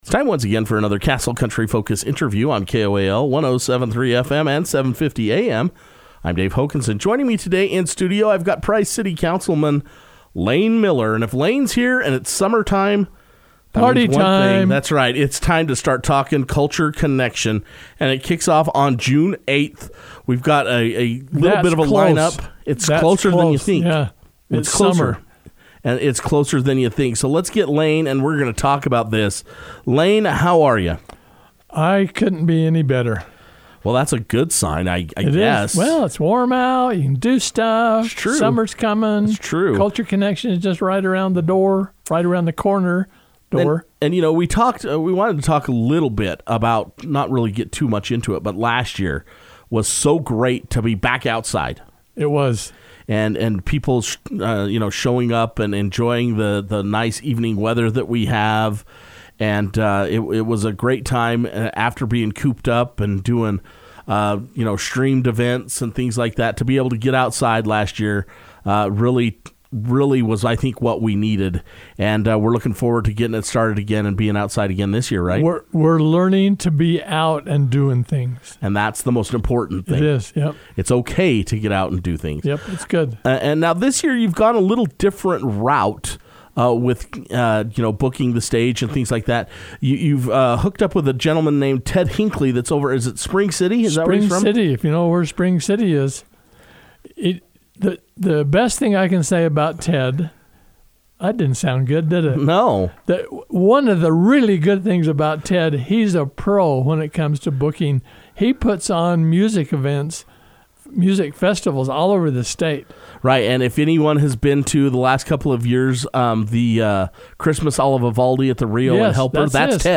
Price City Council Member, Layne Miller was able to stop by Castle Country Radio to share all the details about the event.